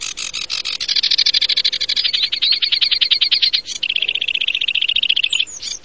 Forapaglie
Acrocephalus schoenobaenus
Un esplosivo ‘touc’, ed un grattato ‘cirr’. Canto più vario e veloce di quello della Cannaiola: un sonoro e rapido ‘medley’ di note acute, melodiose, più volte ripetute e frammiste a trilli, come di canarino, e frasi di altri uccelli.
Forapaglie.mp3